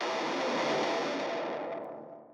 Distortion FX.wav